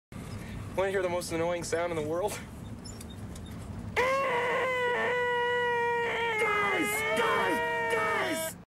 Play, download and share most annoying sound in the wor original sound button!!!!
most-annoying-sound-in-the-wor.mp3